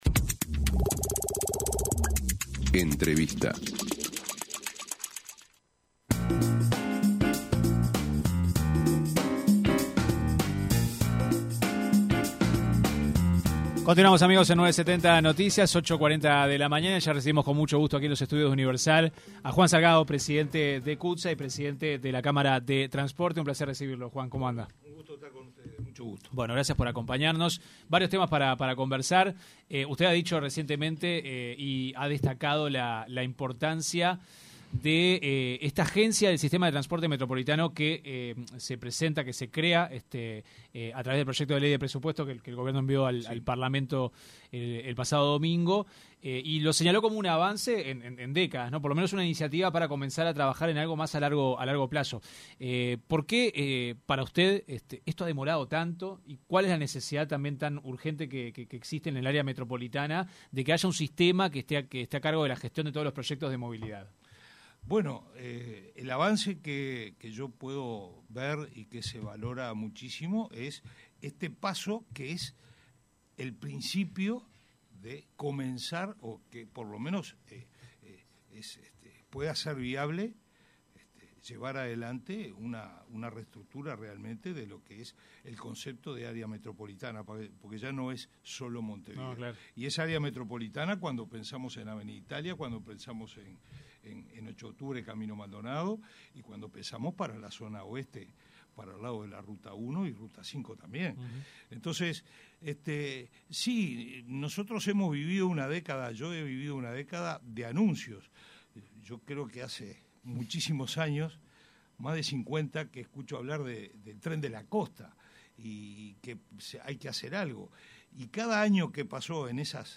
en entrevista con 970 Noticias